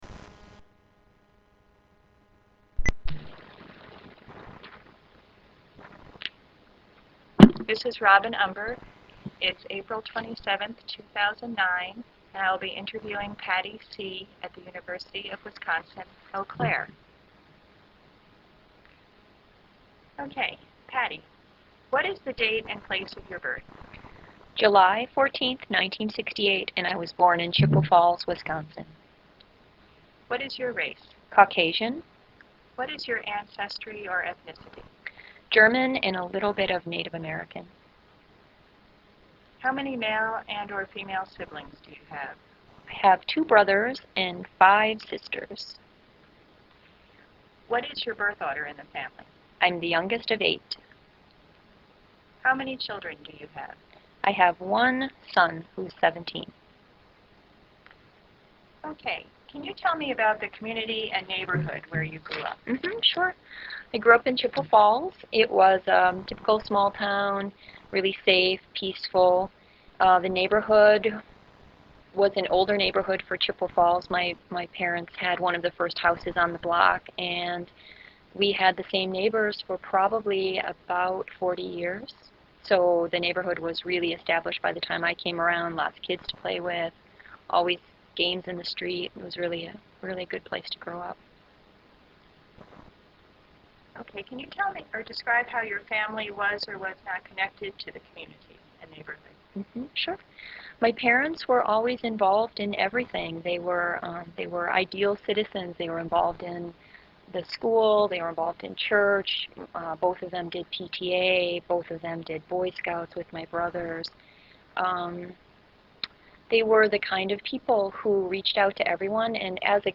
This interview is part of an oral history project conducted in honor of 25th anniversary of the Women's Studies Program at the University of Wisconsin - Eau Claire.